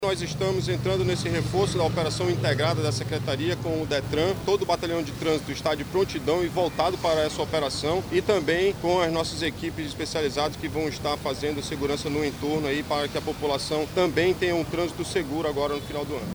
A Polícia Militar, atua no patrulhamento integrado da cidade durante a Operação de Fim de Ano, conforme destaca o comandante-geral da corporação, coronel Klinger Paiva.